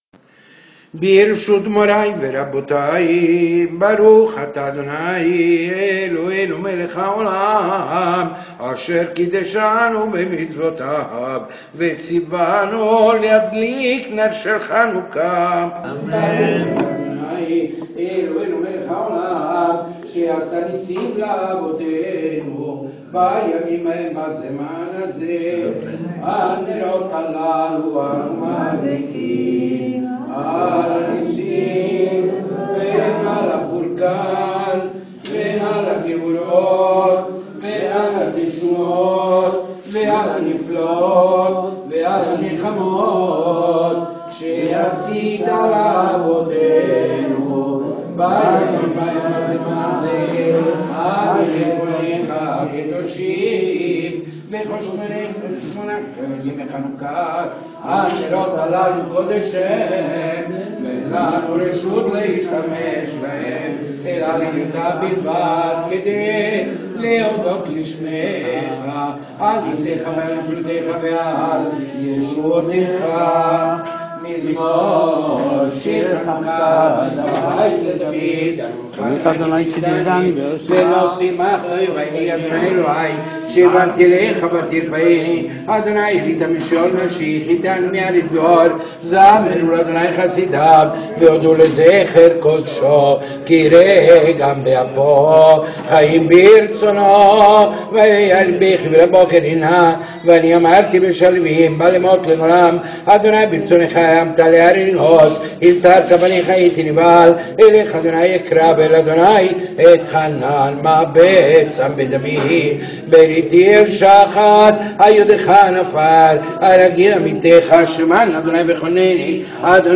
rito fiorentino